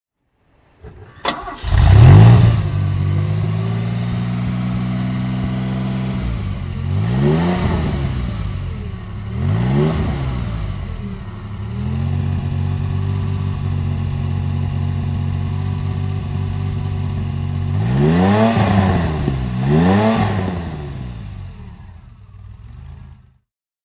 DeLorean DMC-12 (1981) - Starting and idling